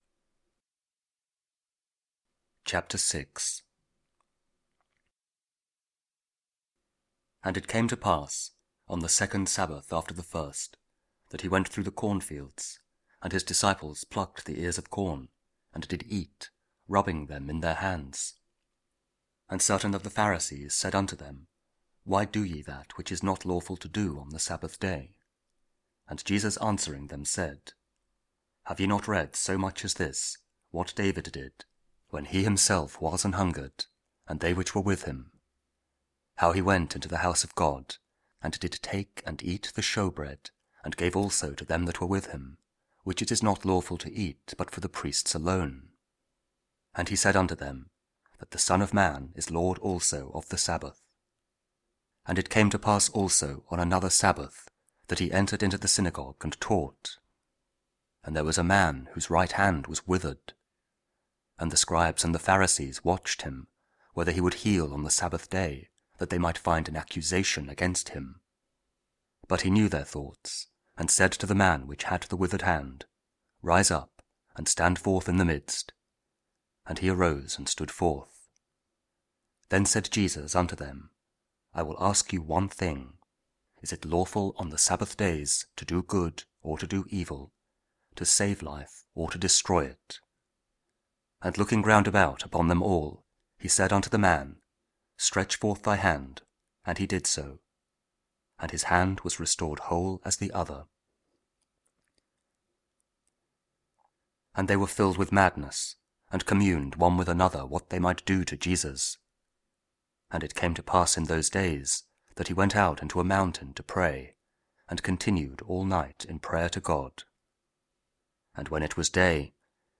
Luke 6: 43-49 – Week 23 Ordinary Time, Saturday (King James Audio Bible KJV, Spoken Word)